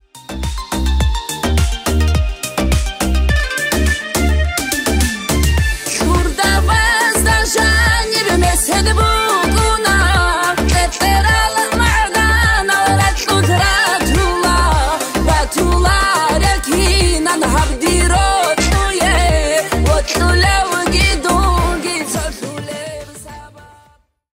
дагестанские # кавказские